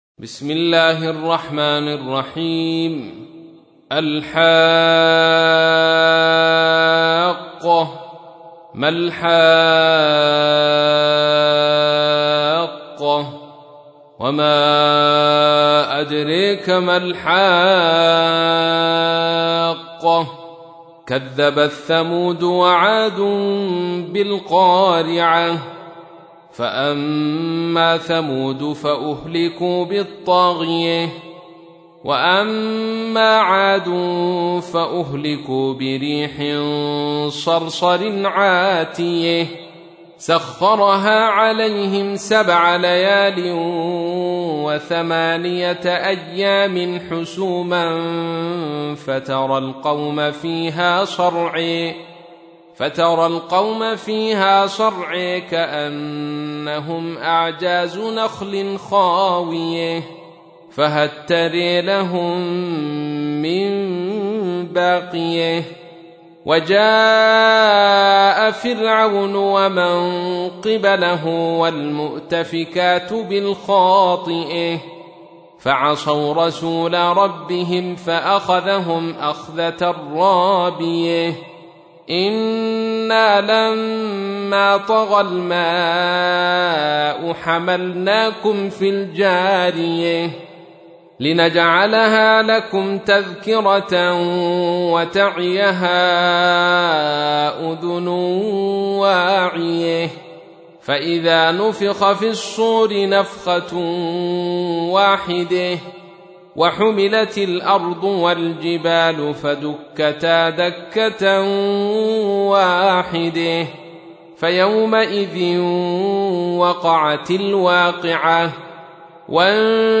تحميل : 69. سورة الحاقة / القارئ عبد الرشيد صوفي / القرآن الكريم / موقع يا حسين